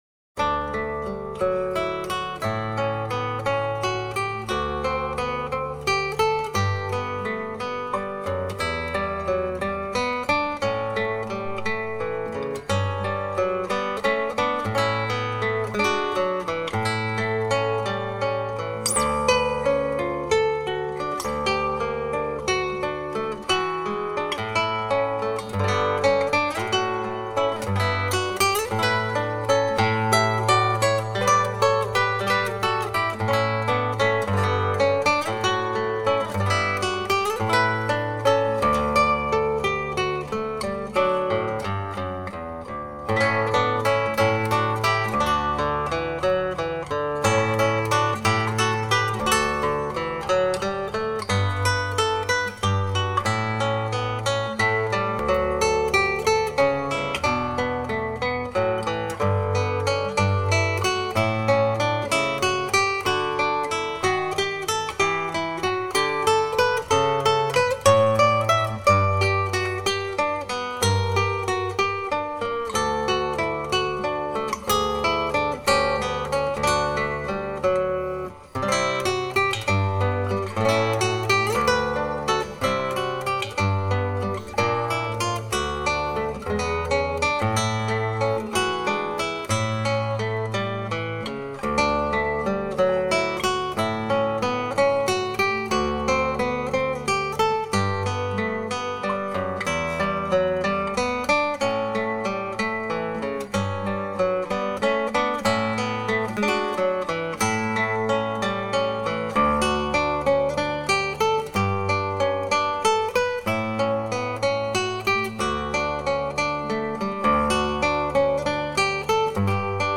923   07:10:00   Faixa:     Valsa